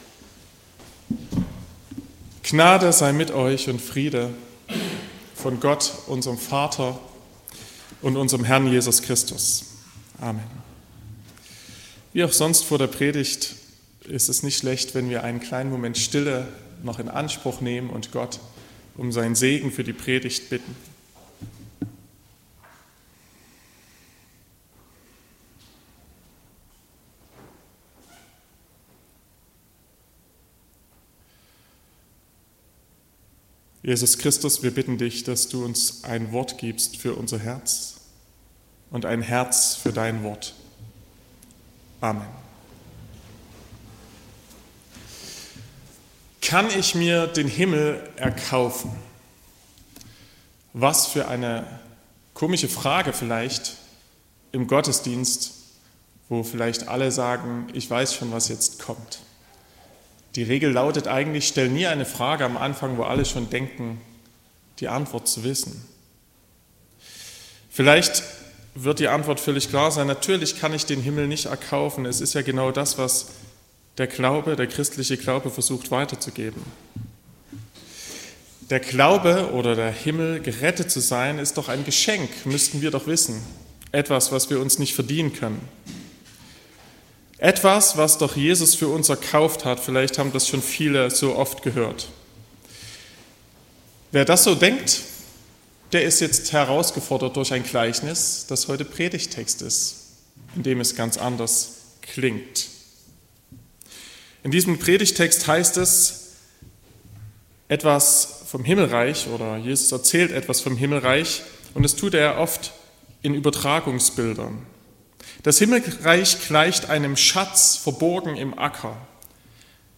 28.07.2024 – Gottesdienst
Predigt und Aufzeichnungen
Predigt (Audio): 2024-07-28_Unvergleichlich.mp3 (12,8 MB)